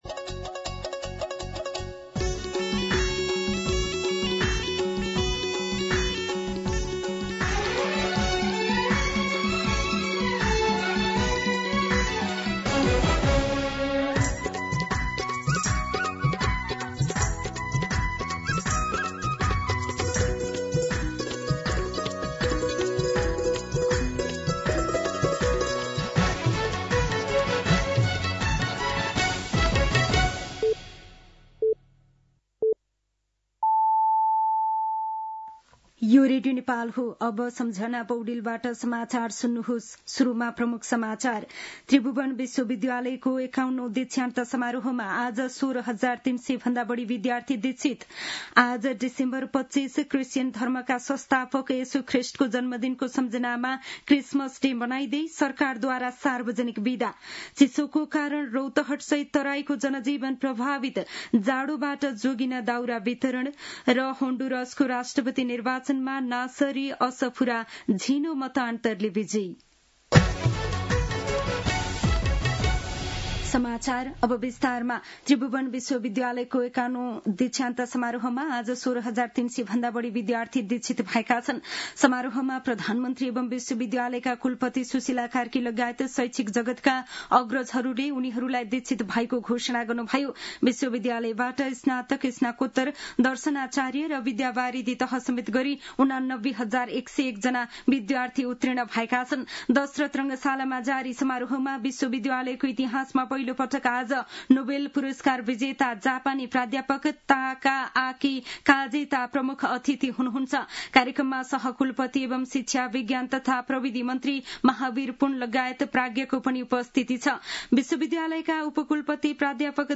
दिउँसो ३ बजेको नेपाली समाचार : १० पुष , २०८२
3-pm-Nepaki-News.mp3